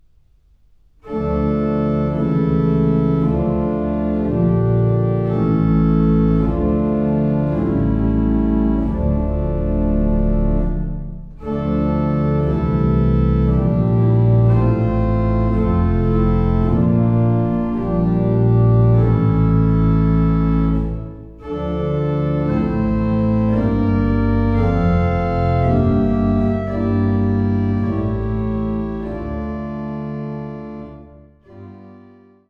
Geißler-Orgel Uebigau